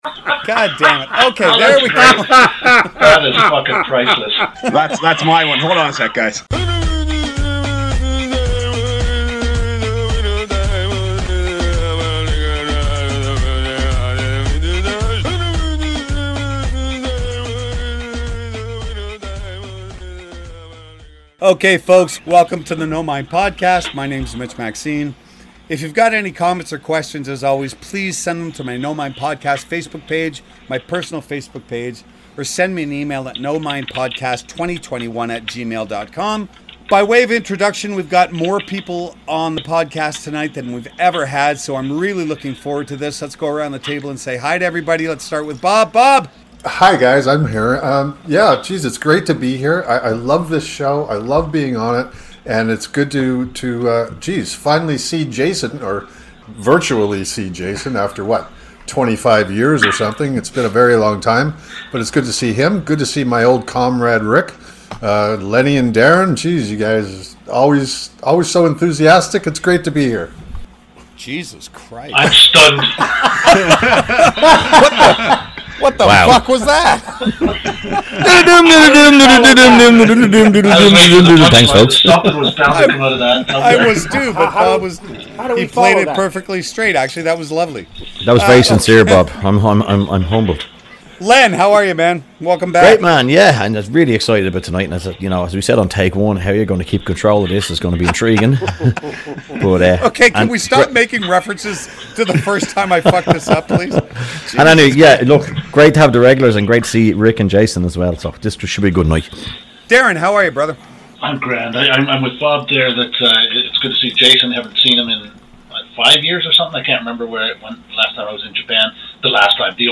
A few martial arts louts sitting around and talking about that oh-so elusive mistress, inspiration.